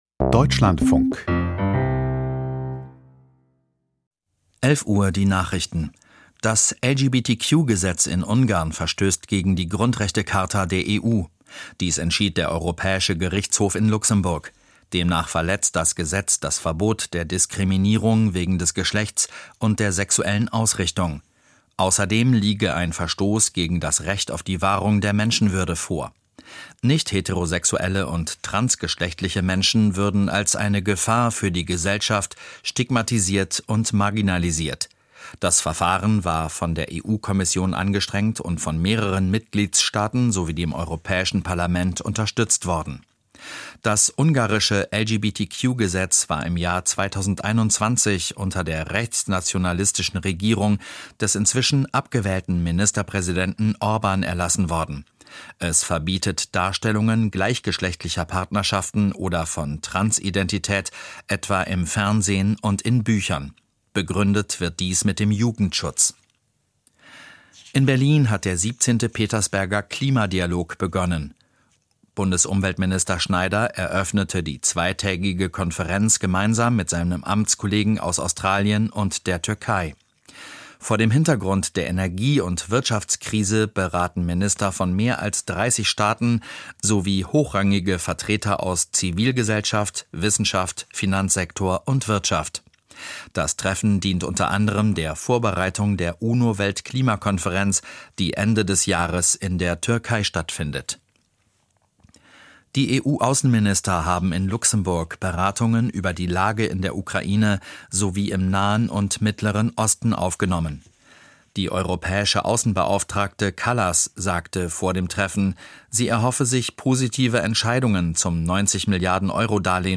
Die Nachrichten vom 21.04.2026, 11:00 Uhr
Aus der Deutschlandfunk-Nachrichtenredaktion.